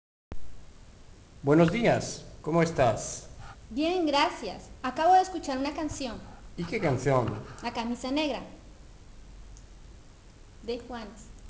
Lien Diálogo